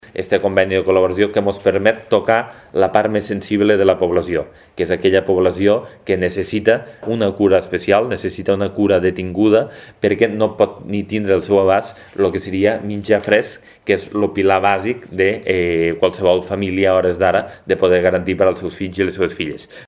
En aquest sentit, el president del Consell Comarcal del Baix Ebre, Lluís Soler, ha volgut agrair el gest realitzat per l’entitat i ha manifestat que “amb la rúbrica d’aquest acord fem un pas endavant per tal de continuar estant al costat i al servei de totes aquelles famílies més desafavorides de la comarca, i per garantir un servei pioner al territori”.
(tall de veu) Lluís Soler explica la finalitat del projecte Tiquet Fresc